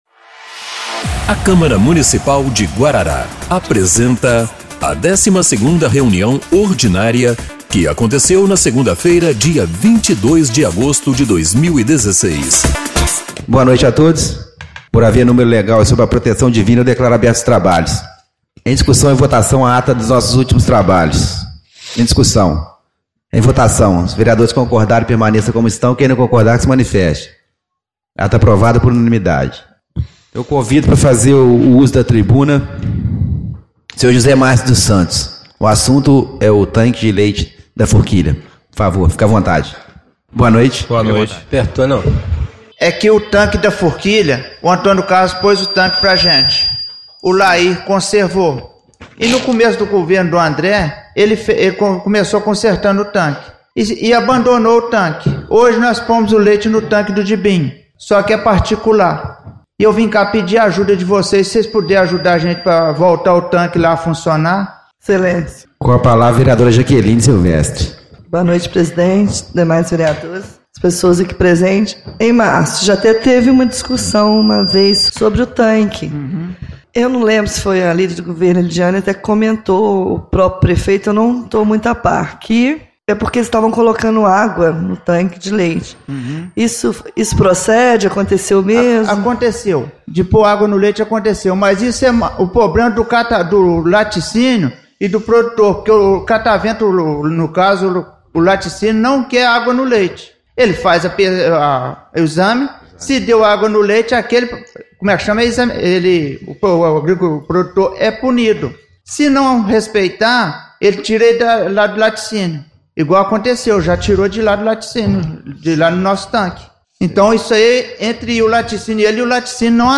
12ª Reunião Ordinária de 22/08/2016